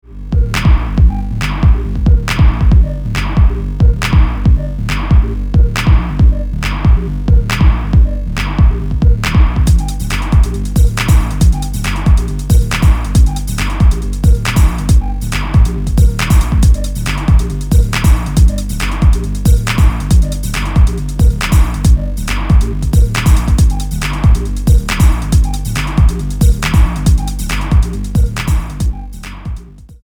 supplier of essential dance music
Electro House Techno